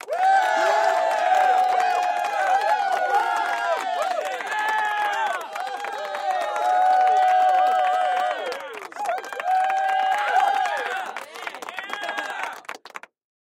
Звуки кричащей толпы
Поздравления и крики толпы